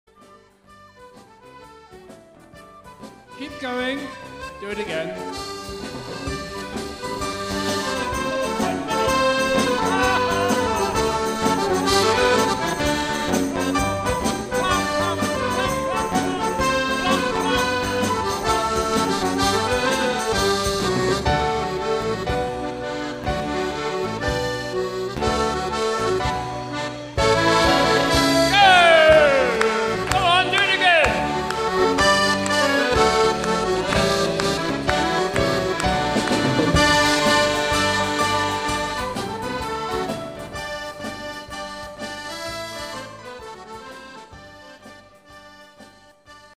recorded at the NAC in Stoneleigh